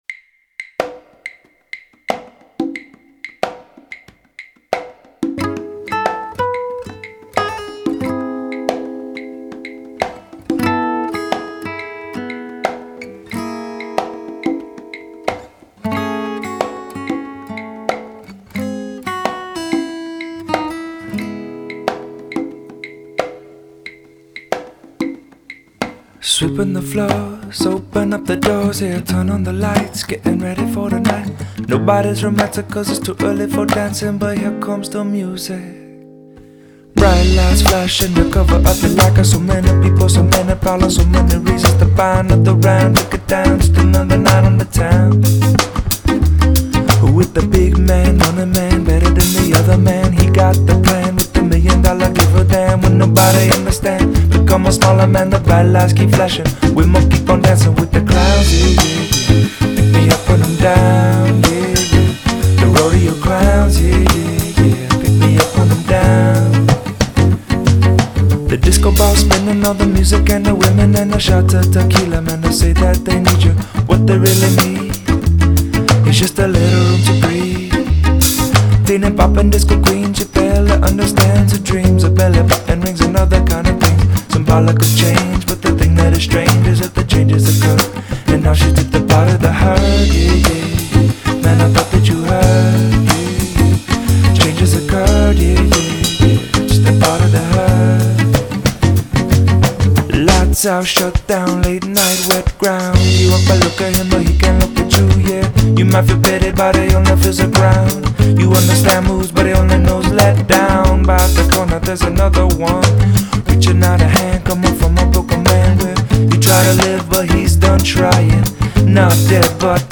西洋音樂
他那非常之柔和的嗓音就是會讓人感到輕鬆而舒適
並聆聽吉他混合著雷鬼樂和白人rapping唱腔